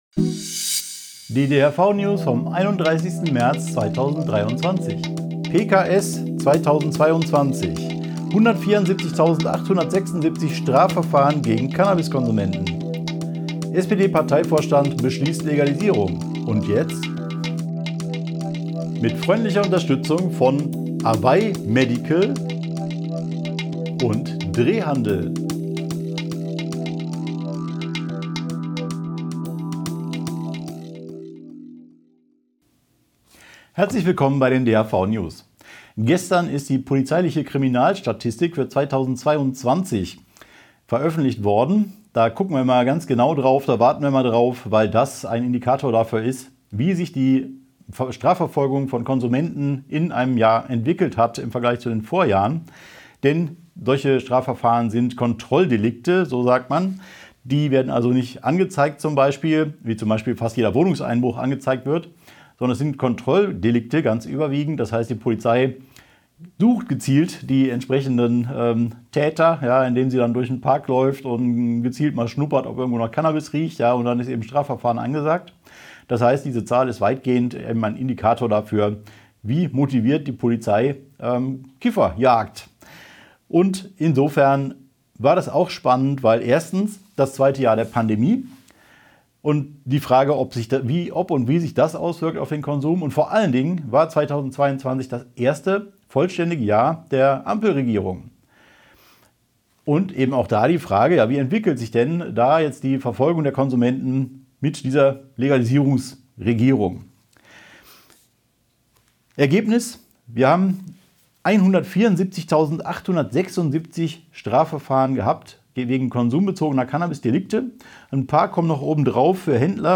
DHV-Video-News #374 Die Hanfverband-Videonews vom 31.03.2023 Die Tonspur der Sendung steht als Audio-Podcast am Ende dieser Nachricht zum downloaden oder direkt hören zur Verfügung.